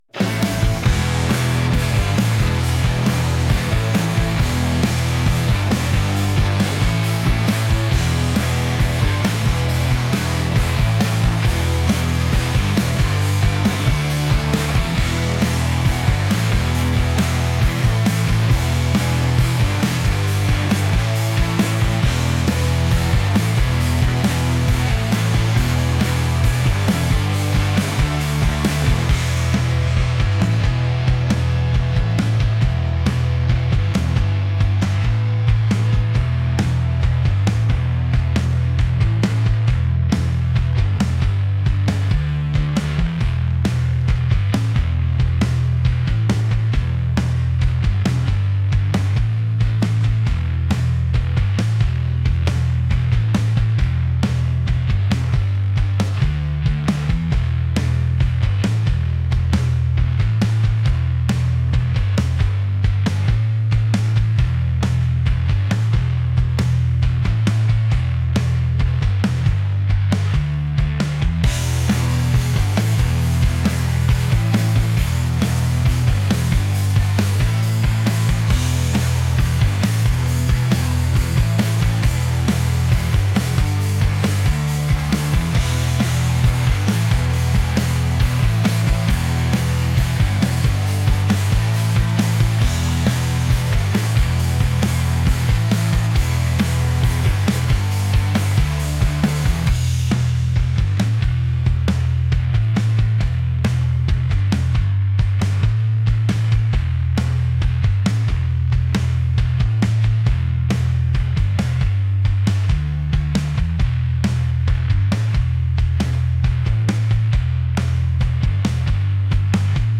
energetic | rock